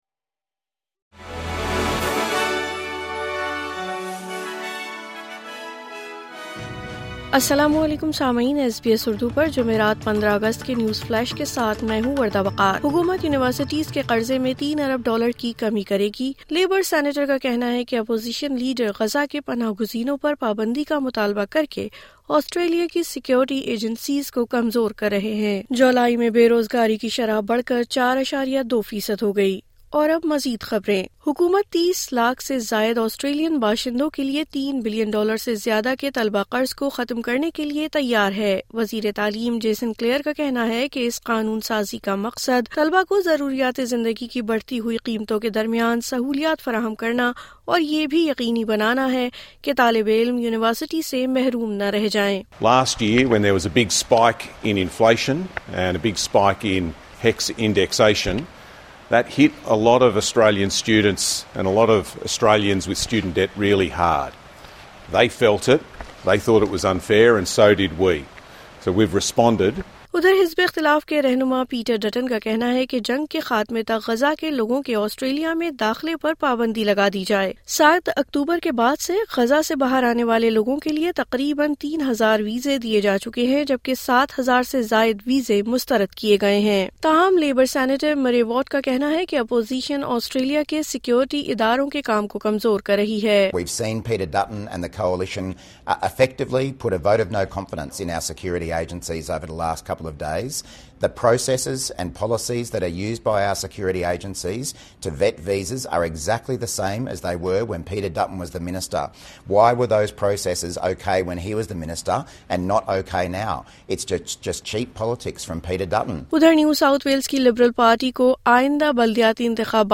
نیوز فلیش جمعرات 15 اگست 2024: حکومت یونیورسٹی کے قرضے میں 3 ارب ڈالر کی کمی کرے گی